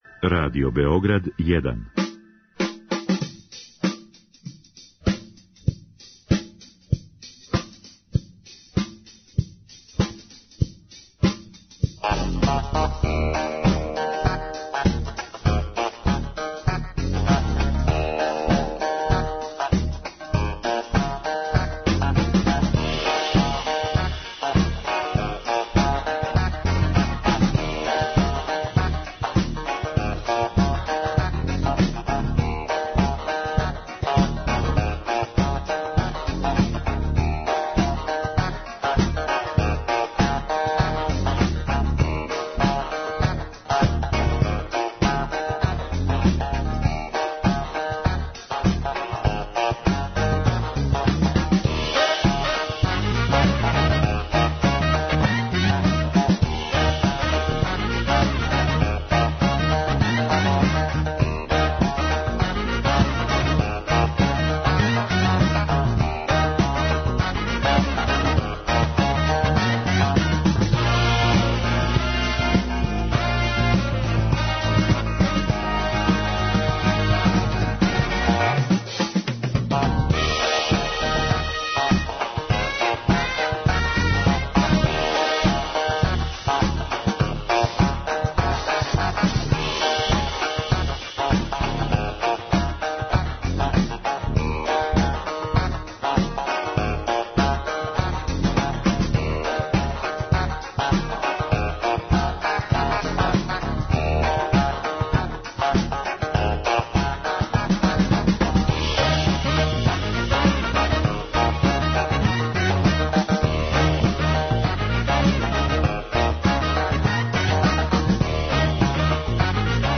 Због заиста специјалних гостију ова емисија уживо ће се емитовати три сата!